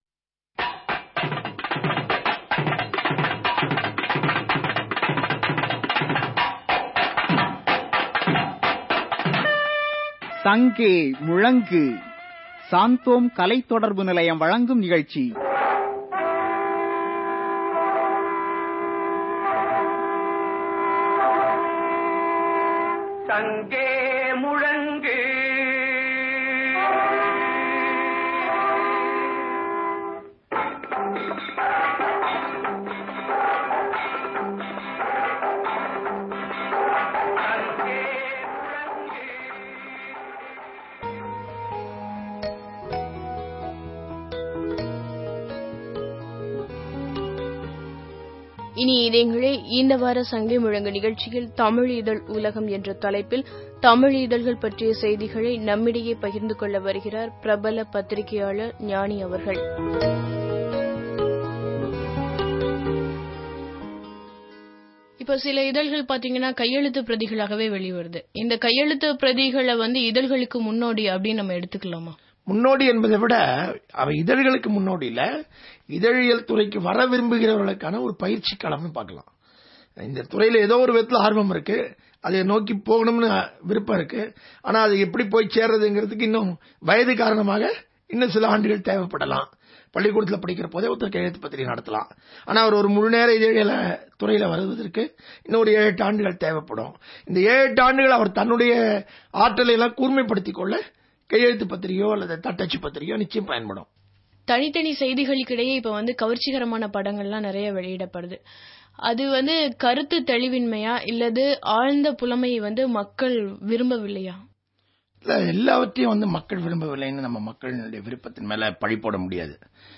Directory Listing of mp3files/Tamil/People's Voice (SANGEA MUZHNGU)/Gnani Interview/ (Tamil Archive)